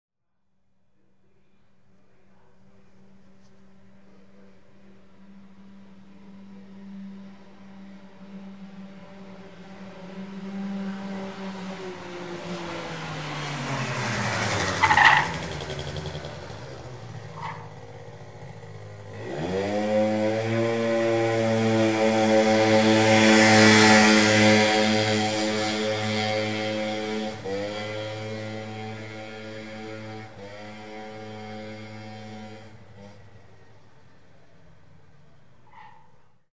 mobylette.mp3